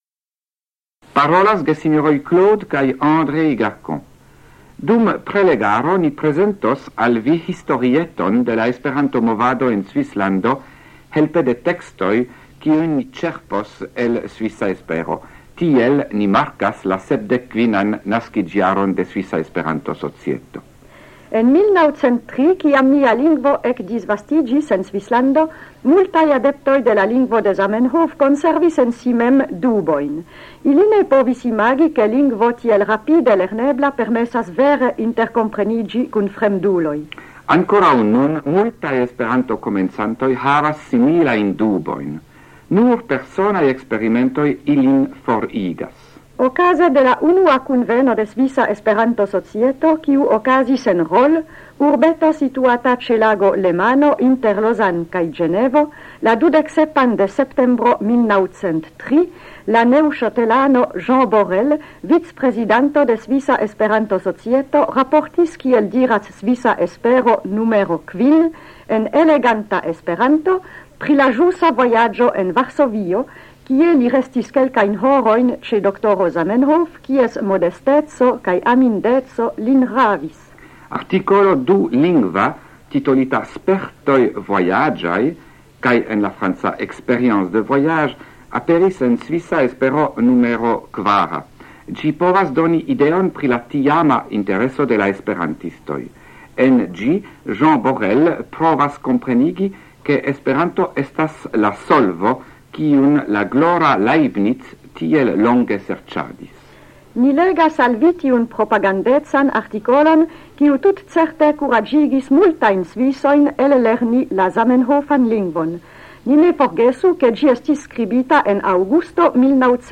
Radioprelegoj en la jaro 1978